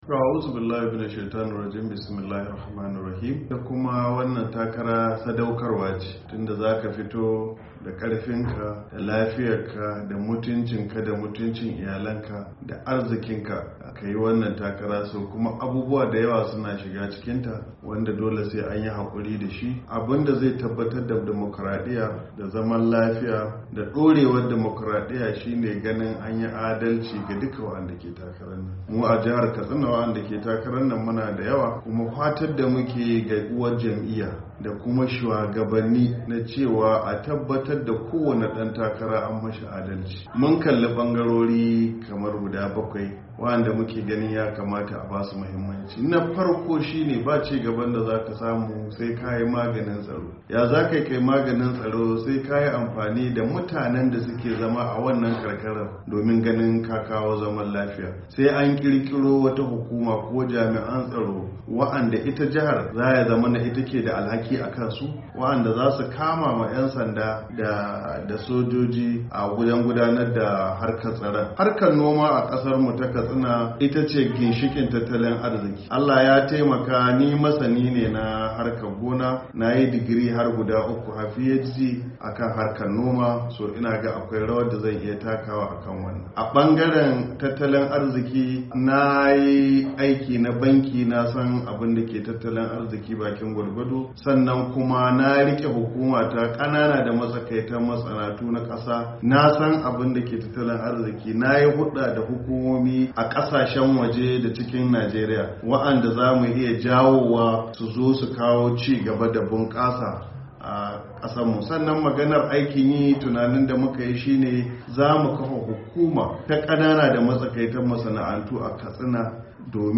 Dakta Radda ya bayyana hakan ne a wata hira ta musamman da muryar Amurka a birnin tarayyar kasar Abuja a daidai lokacin da ake tunkarar zaben fidda gwanin jam’iyyun siyasar Najeriya ciki har da jam’iyyar APC mai mulki da babbar jam’iyyar adawa ta PDP a Najeriya,.